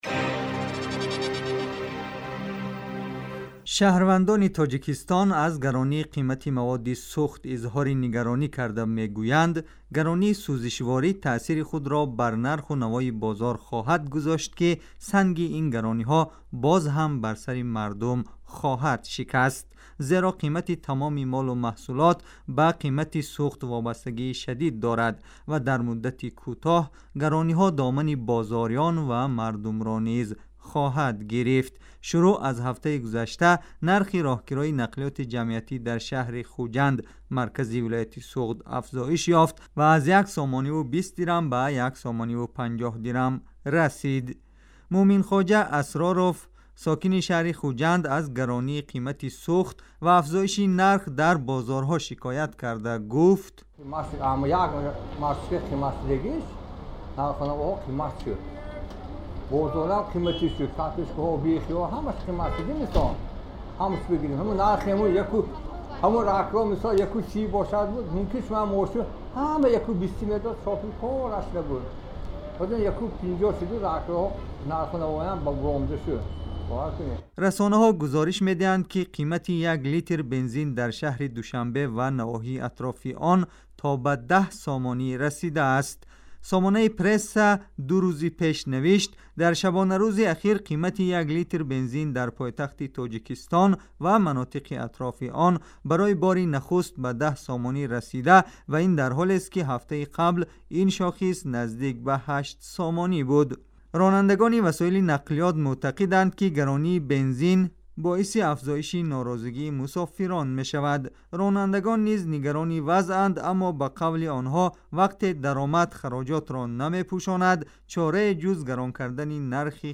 گزارش ویژه : نگرانی مردم از پیامد های گرانی قیمت سوخت در تاجیکستان